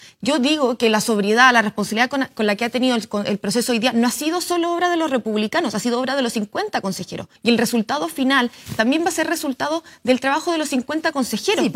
En entrevista dada al programa «Estado Nacional, a través de la pantalla de TVN, la oriunda de Osorno y presidenta del Consejo Constitucional, Beatriz Hevia, abordó diversas temáticas respecto al trabajo realizado por los 50 consejeros y específicamente sobre las emniendas.